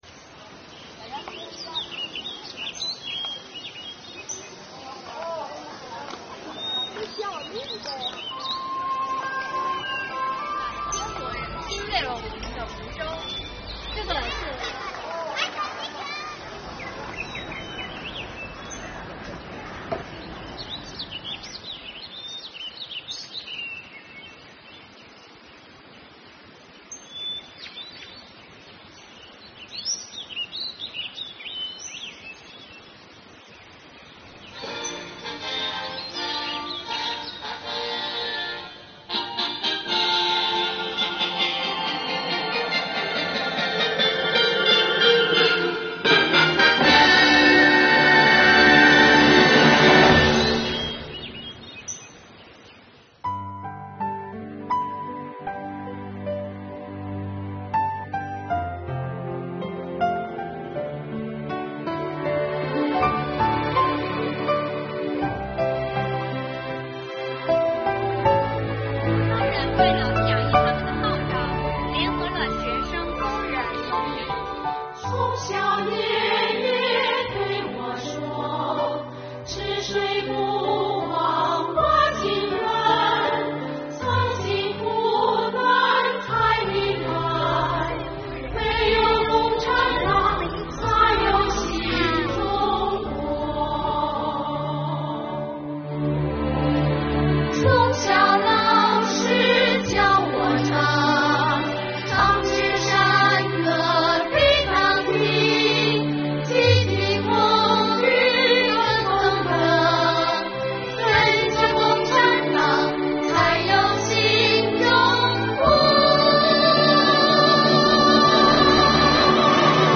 “从小爷爷对我说，吃水不忘挖井人……”几名税务干部嘹亮的歌声在梧州市珠山公园里响起，情感饱满的歌声引来了众多群众的驻足围观和点赞。
近日，国家税务总局梧州市万秀区税务局为庆祝中国共产党成立100周年，组织税务干部走进梧州市珠山公园开展以“感党恩 跟党走 红色歌曲大家唱”为主题的快闪活动。